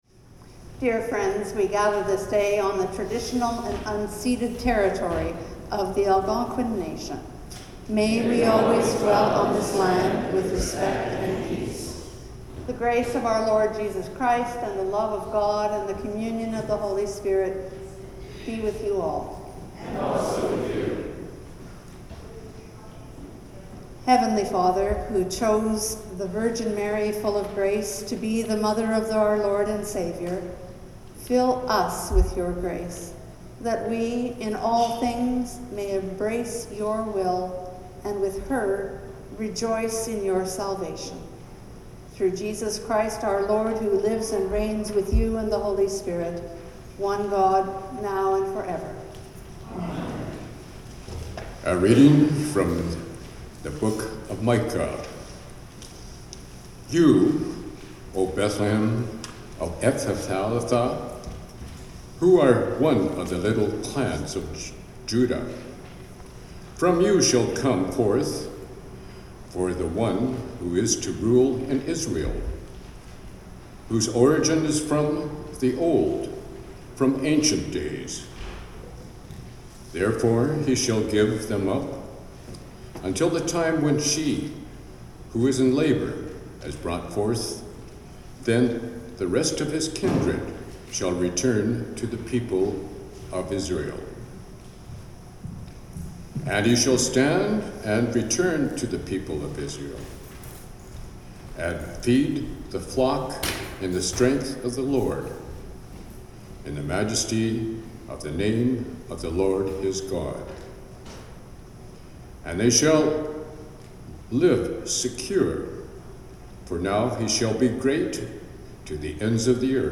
Greeting, Land Acknowledgment & Collect of the Day
The Lord’s Prayer (sung) Doxology & Blessing